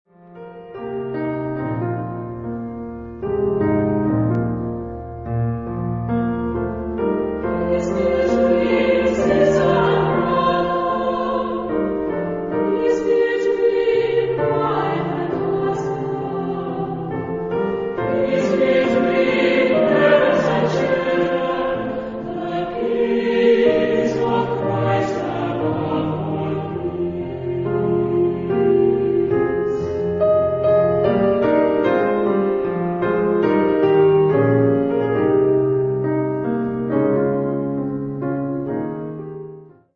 Genre-Style-Forme : Sacré ; Motet
Caractère de la pièce : simple
Type de choeur : SA  (2 voix égales )
Instruments : Piano (1)
Tonalité : fa majeur